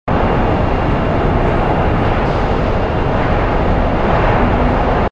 rumble_station_small.wav